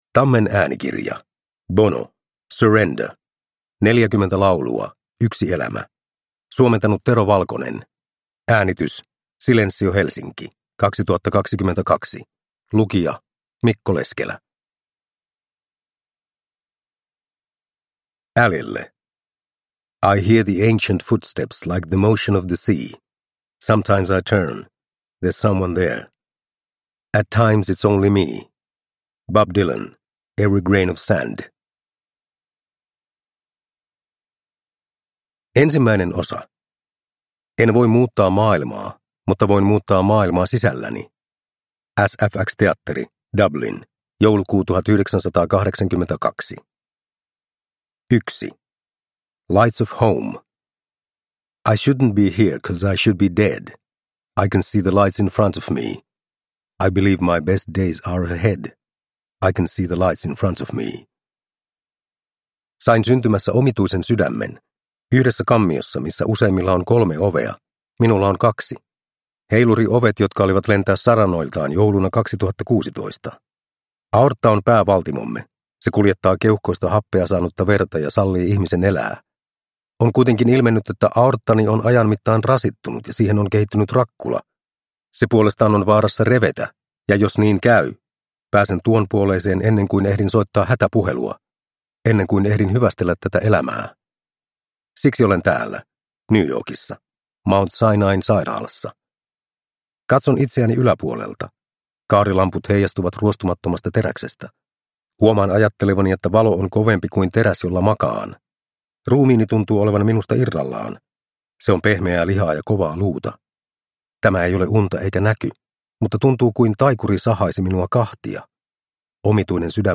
Surrender – Ljudbok – Laddas ner